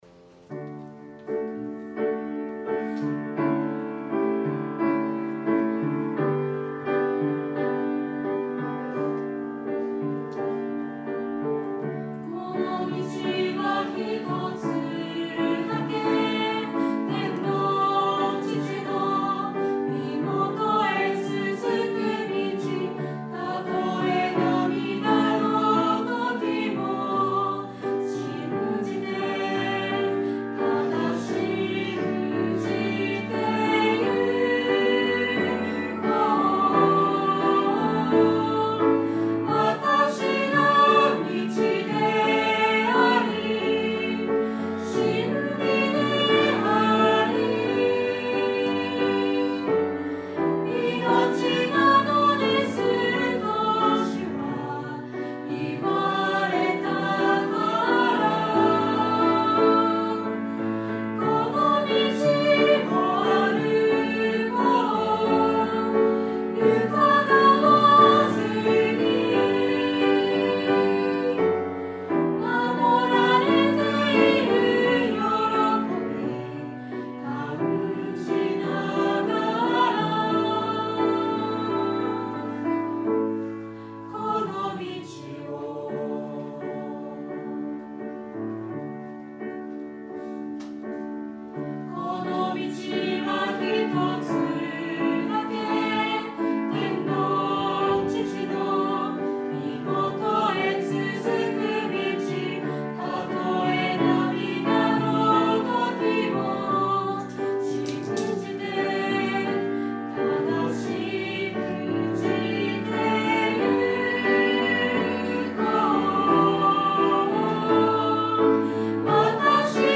ユースによる賛美奉仕です。
ユース賛美ライブラリ